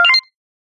get_xp_01.ogg